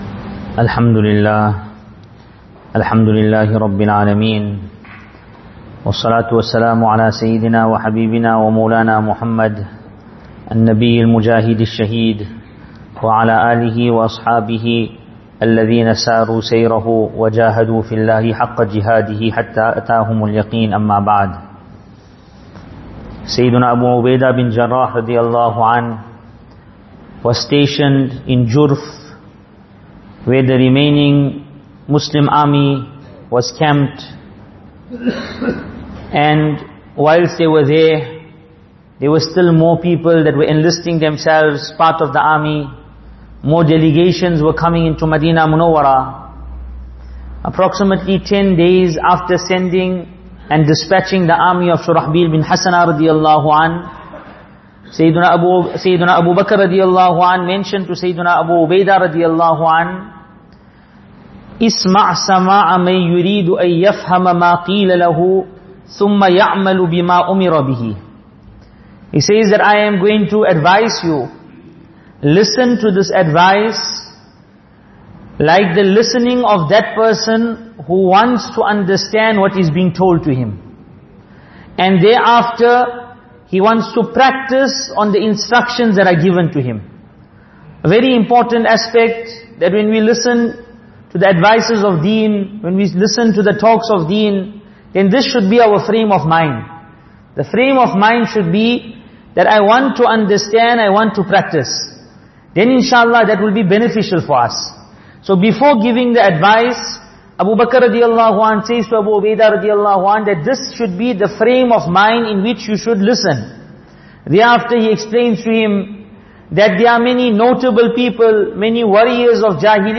Ramadaan Lectures